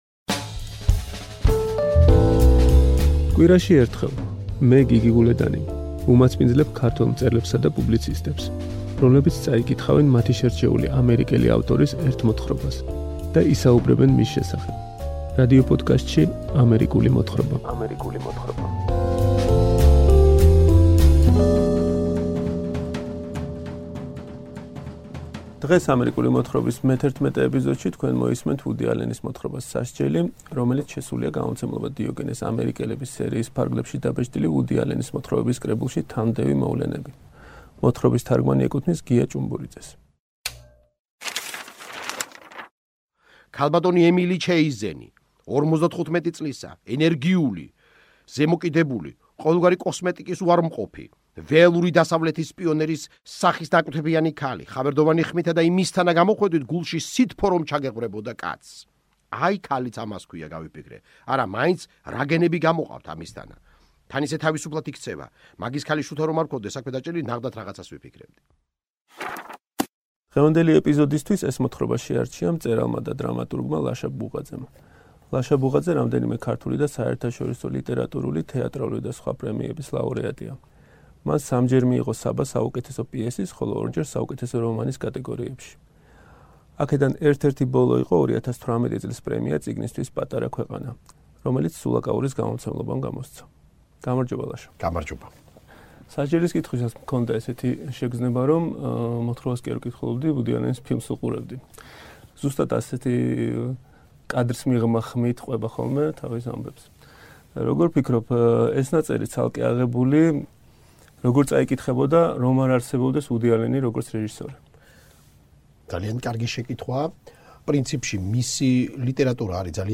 ლაშა ბუღაძე კითხულობს ვუდი ალენის “სასჯელს”
რადიოპოდკასტ “ამერიკული მოთხრობის” მეთერთმეტე გადაცემაში თქვენ მოისმენთ ვუდი ალენის მოთხრობას “სასჯელი”, რომელიც “ამერიკული მოთხრობისთვის” წაიკითხა მწერალმა ლაშა ბუღაძემ. მოთხრობა “სასჯელი” შესულია გამომცემლობა “დიოგენეს” “ამერიკელების” სერიის ფარგლებში დაბეჭდილ ვუდი ალენის მოთხრობების კრებულში...